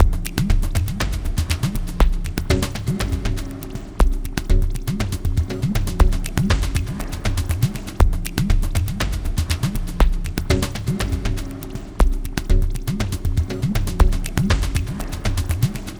Downtempo 06.wav